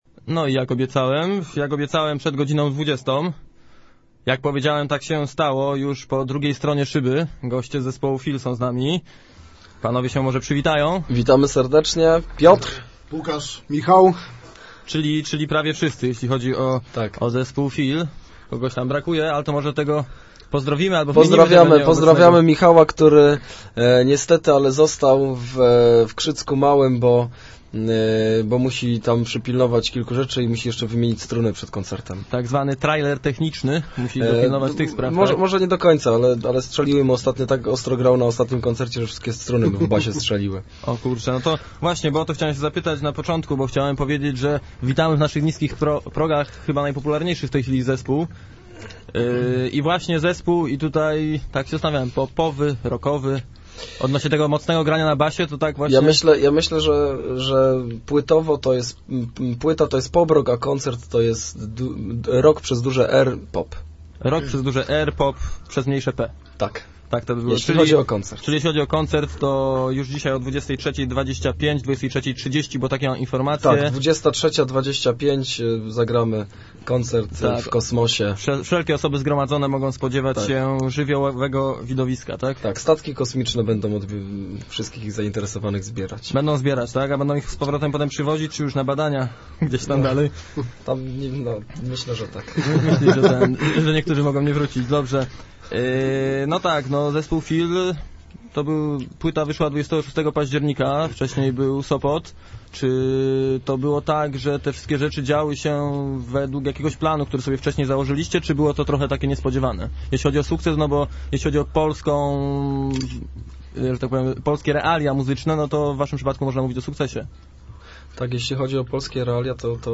Zespół Feel w klubie Cosmos. Zespół Feel w klubie Cosmos + wywiad!